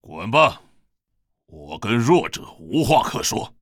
文件 文件历史 文件用途 全域文件用途 Hartz_fw_01.ogg （Ogg Vorbis声音文件，长度3.6秒，100 kbps，文件大小：44 KB） 源地址:地下城与勇士游戏语音 文件历史 点击某个日期/时间查看对应时刻的文件。